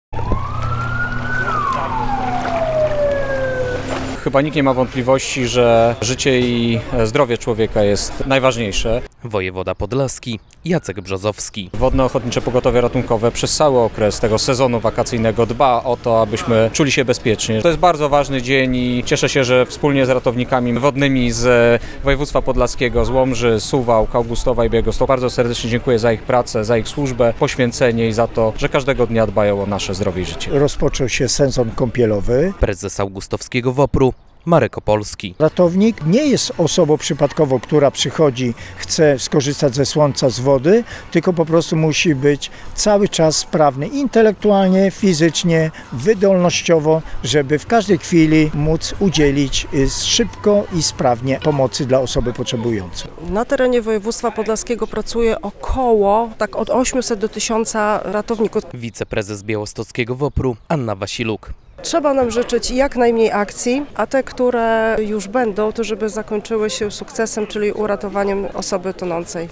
Święto WOPR w Augustowie - relacja